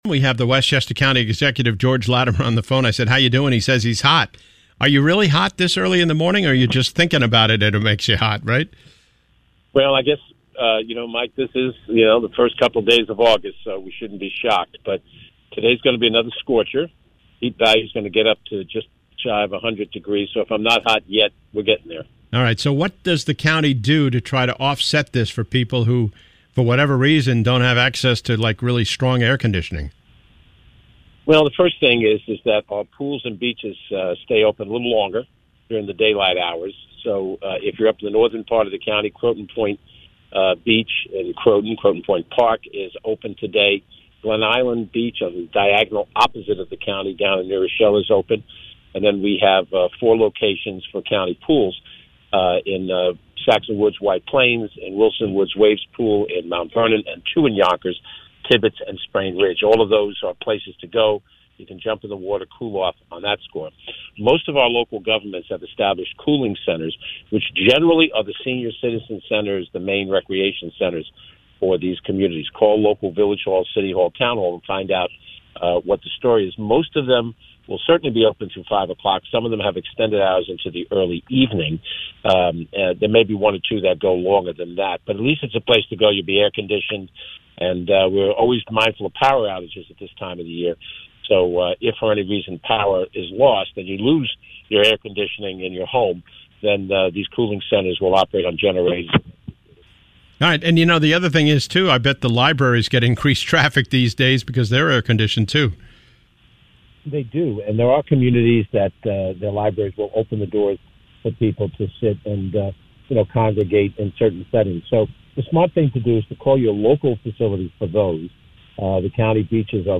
Westchester County executive George Latimer on the heat wave 8-2-24